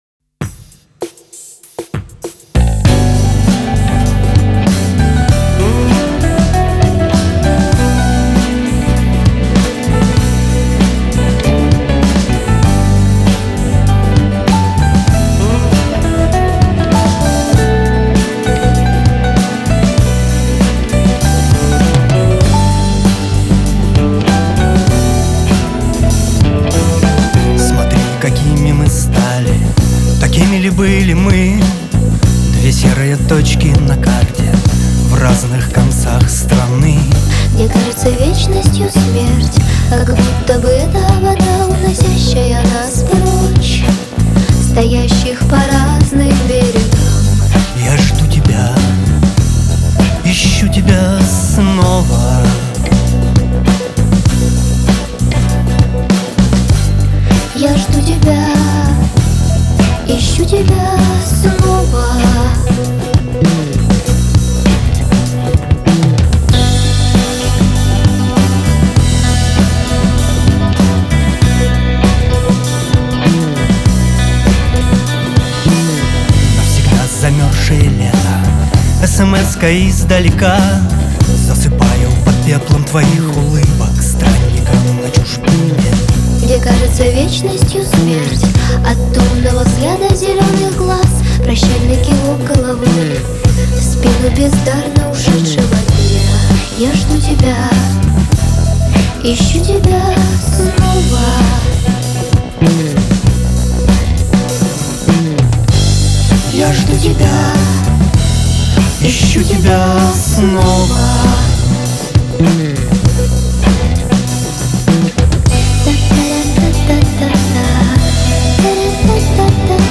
нарулил РОкешник. Жду хейта)
Барабаны живые + драм машина, Bass, Gtr , gtr акустик, Ритм-2, фоно logic.